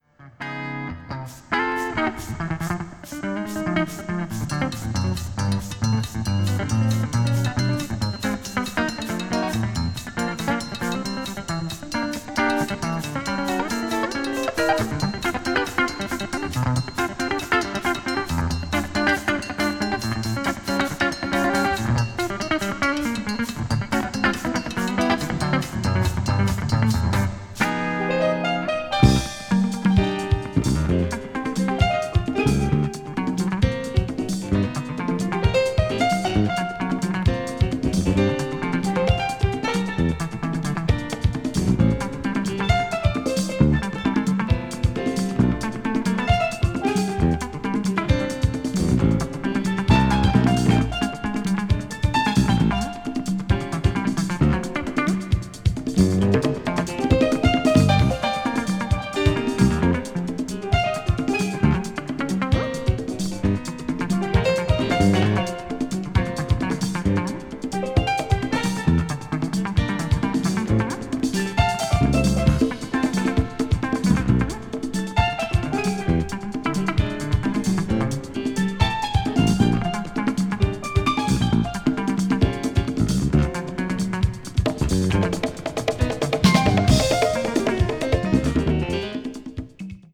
crossover   ethnic jazz   fusion   jazz groove   tropical